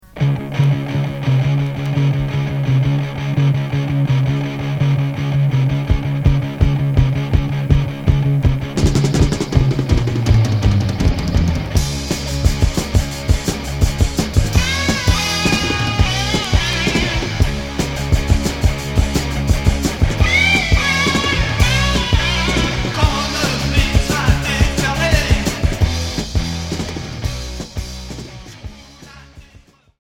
Street punk Unique 45t retour à l'accueil